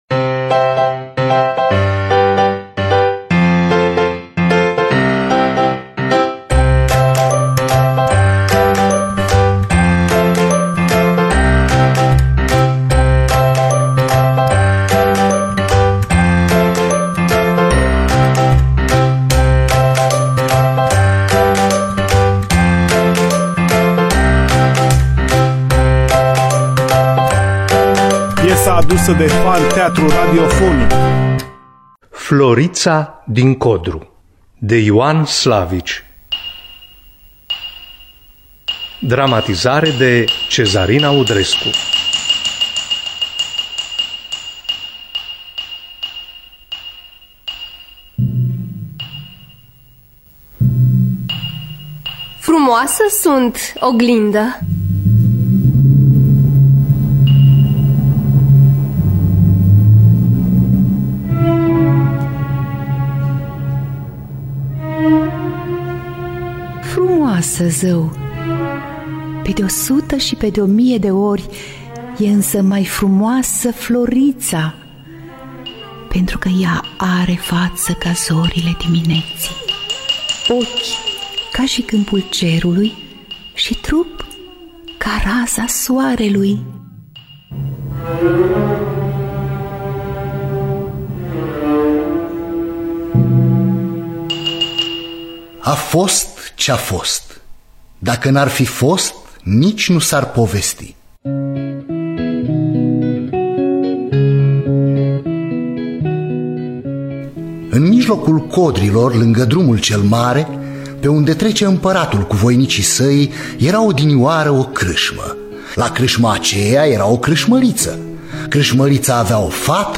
Florița din codru de Ioan Slavici – Teatru Radiofonic Online